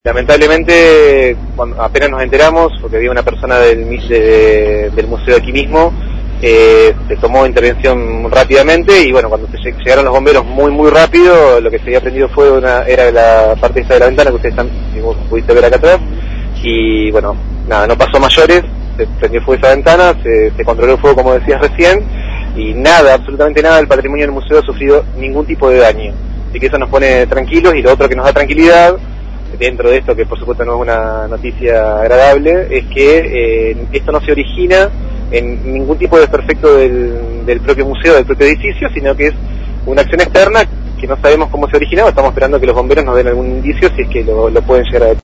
Federico Crisalles, subsecretario de Espacios Culturales, en diálogo con el móvil de LT10 precisó que los bomberos zapadores llegaron “muy rápido” y evitaron que el fuego se propague, afectando únicamente una ventana exterior.